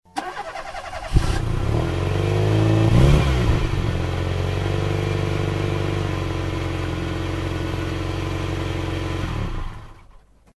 CAR_CRINGE.ogg